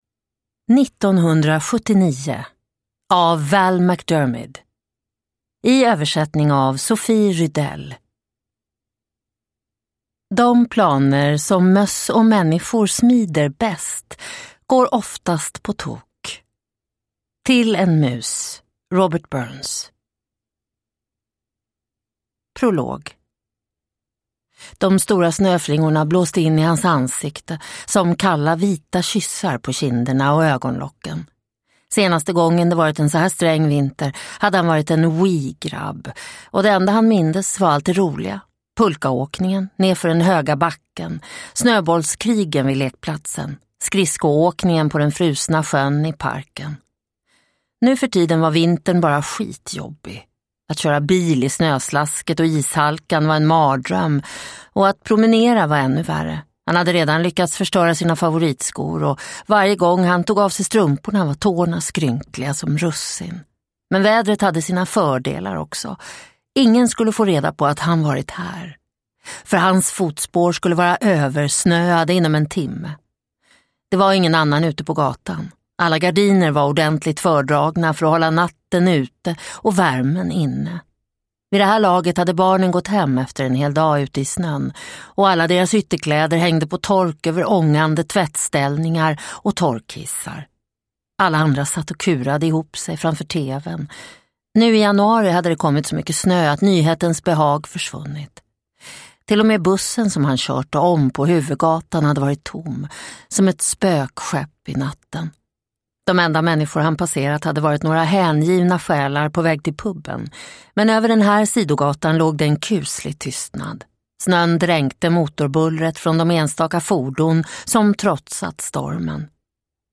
1979 (ljudbok) av Val McDermid